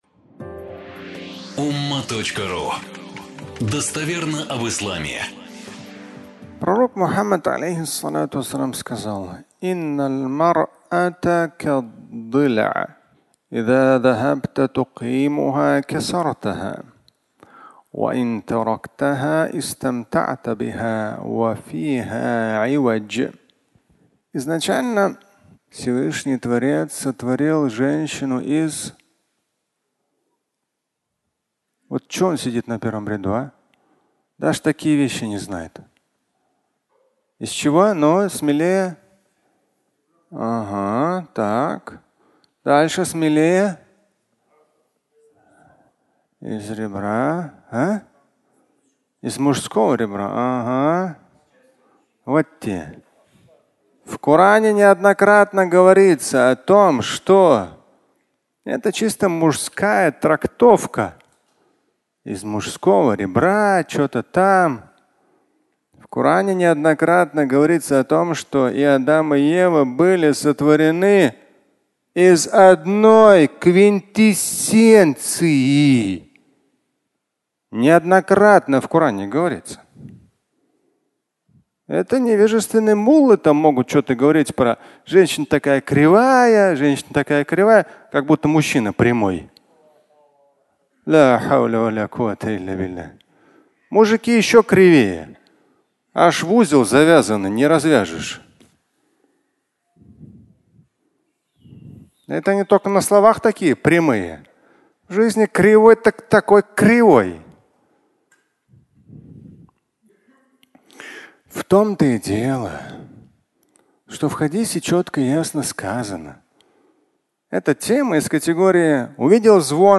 Из ребра (аудиолекция)